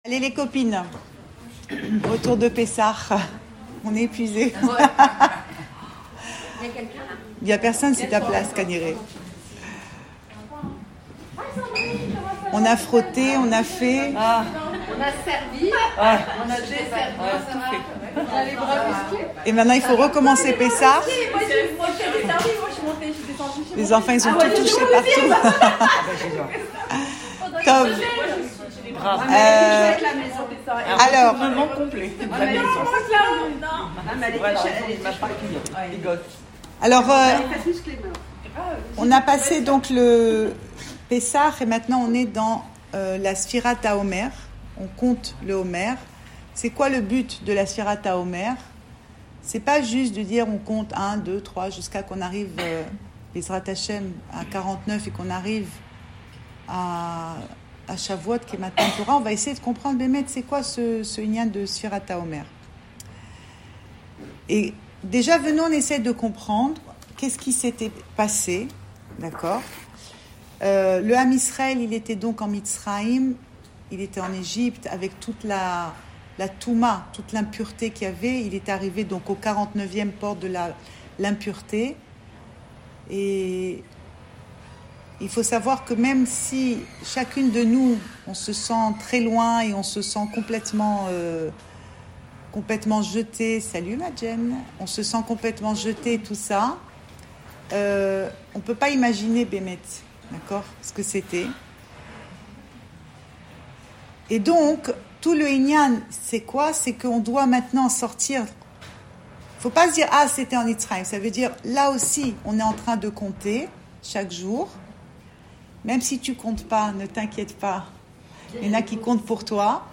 Cours audio Le coin des femmes Pensée Breslev - 27 avril 2022 29 avril 2022 Le compte du Omer : montées et descentes. Enregistré à Tel Aviv